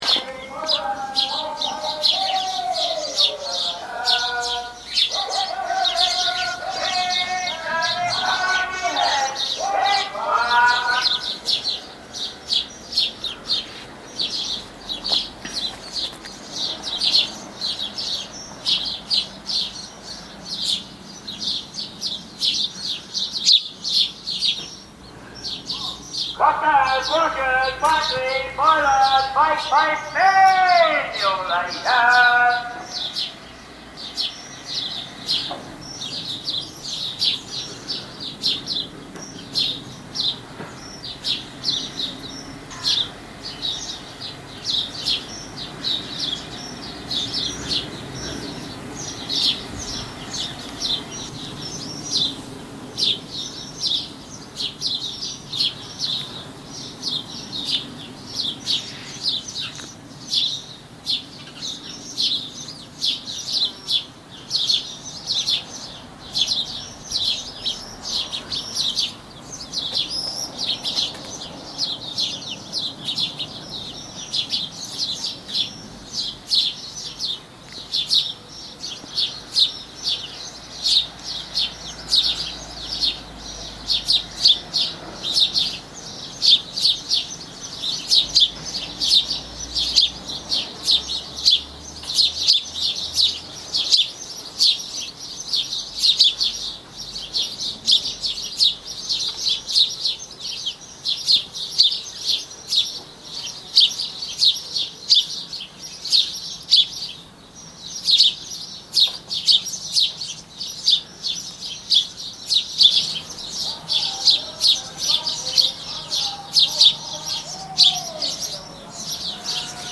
Rag and bone man in an English village
The recording captures him passing down my lane, then the strident chatter of sparrows and the gentle hum of bees in the garden before we hear him again calling in the distance.